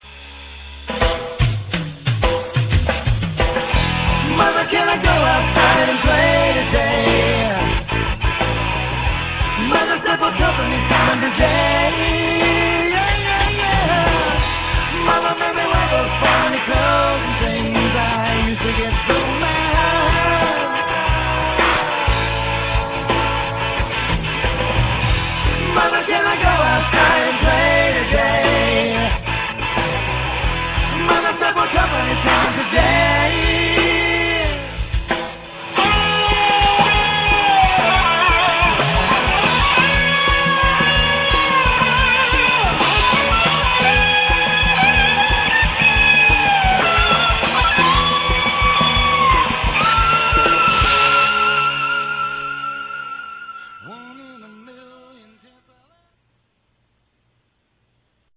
in 16 bit stereo sampled at 44 khz